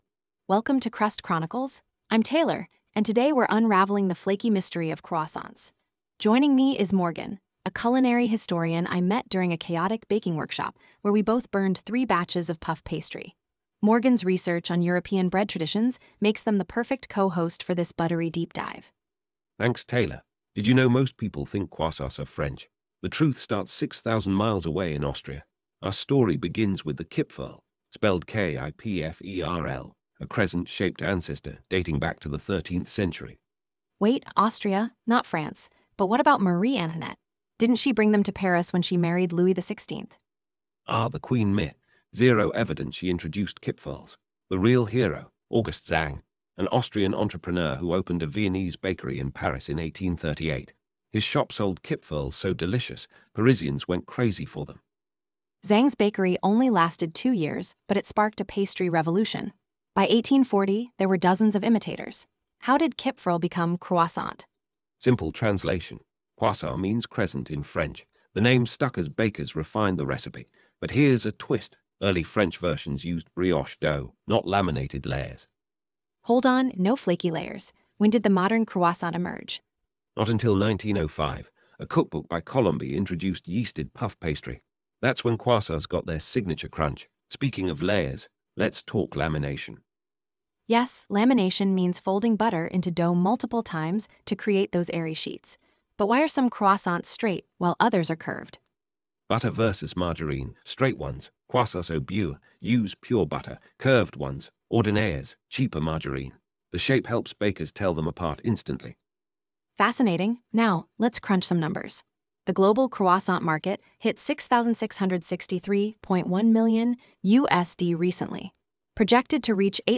A model that enhances speech audio by optimizing for clarity, with configurable denoising and solver parameters.